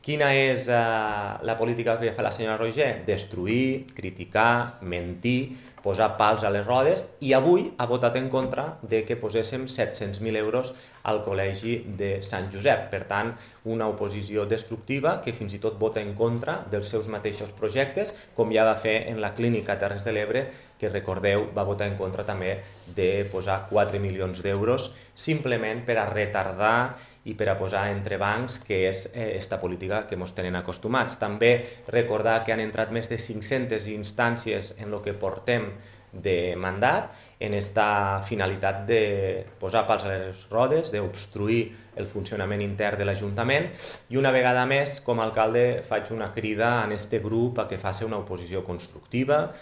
L’’alcalde Jordi Jordan ha recordat que en el mandat anterior es van celebrar 46 plens extraordinaris, 25 dels quals urgents i  ha acusat el grup majoritari al consistori de fer una oposició destructiva…